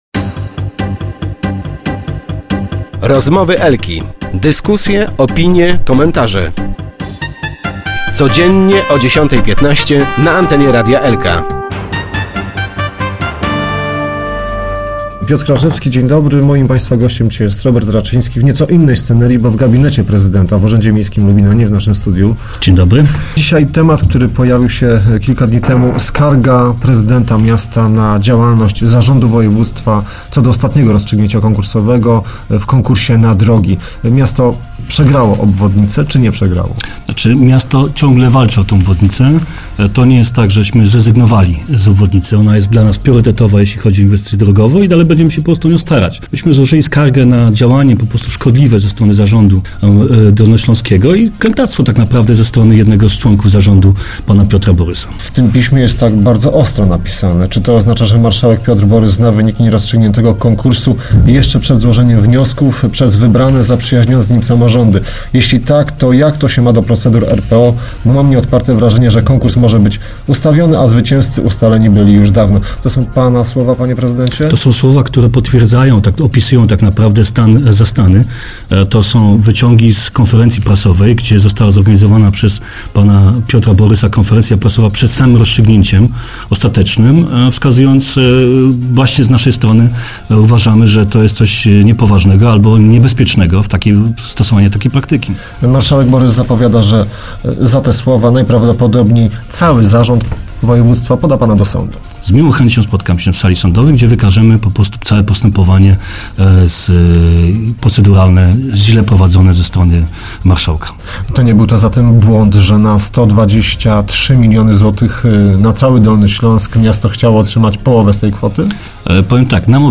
Prezydent i wicemarszałek byli dziś gośćmi porannych rozmów Radia Elka.